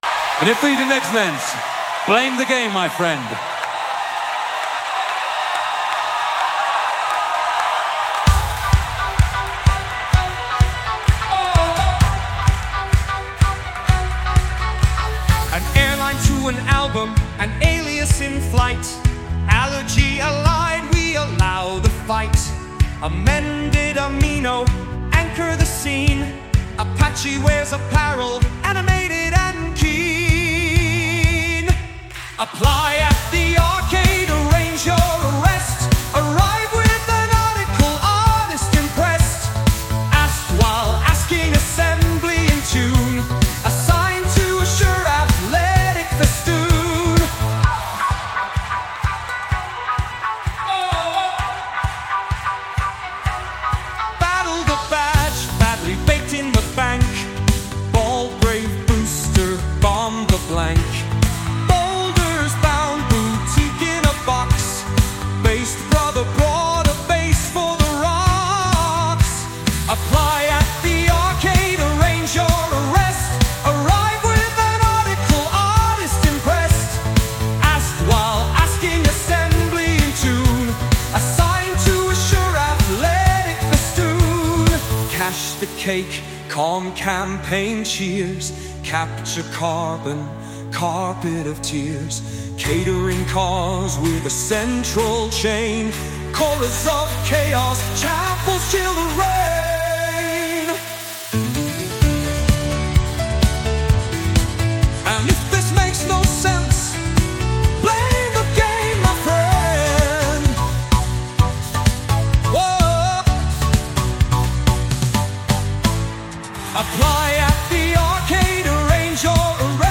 Sound Imported : Generous Nourishment
Sung by Suno